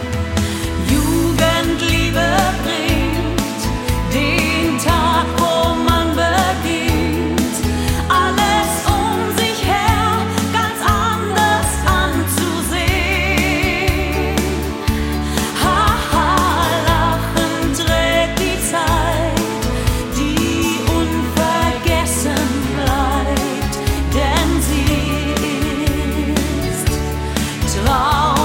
Gattung: Moderner Einzeltitel
Besetzung: Blasorchester
Nun auch für Blasorchester mit Gesang lieferbar!